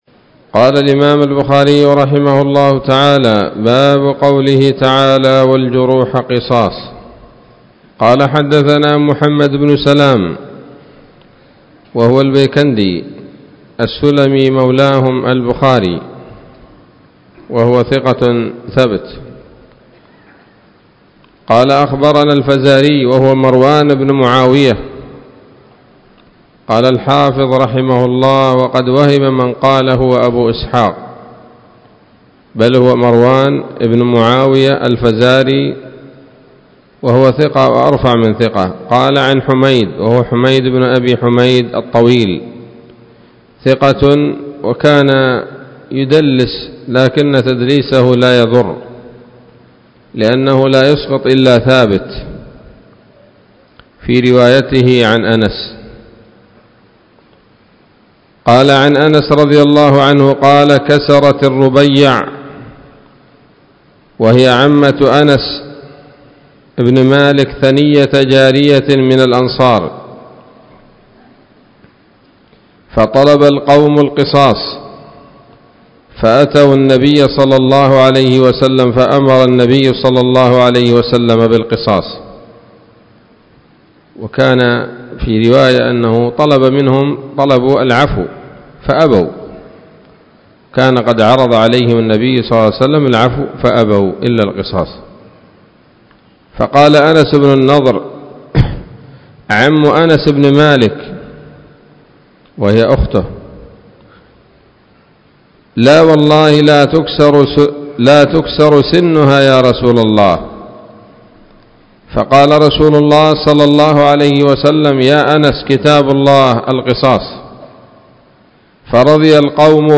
الدرس التسعون من كتاب التفسير من صحيح الإمام البخاري